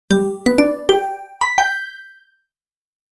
Playful Pizzicato Alert Sound Effect
Description: Playful pizzicato alert sound effect.
Grab attention instantly with this short pizzicato notification tone, featuring a crisp, playful pluck.
Playful-pizzicato-alert-sound-effect.mp3